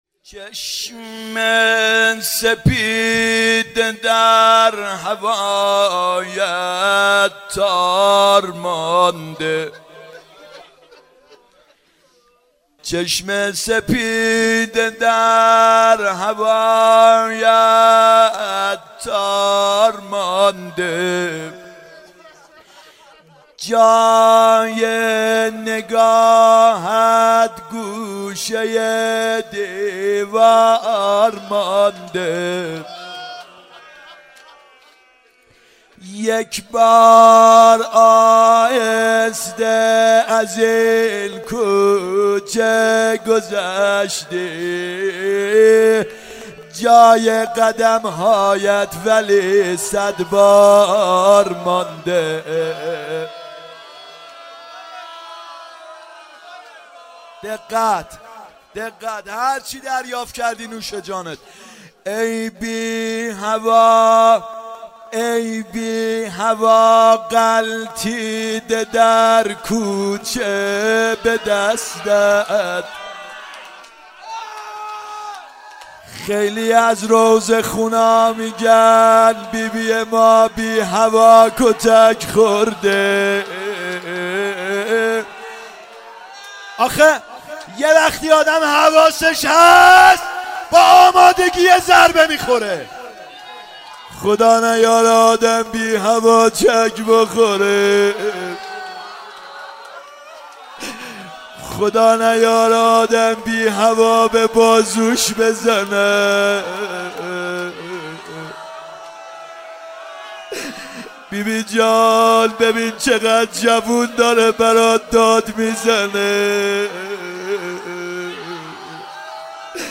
فاطمیه اول هیئت یامهدی (عج)